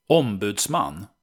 Sv-ombudsman.ogg.mp3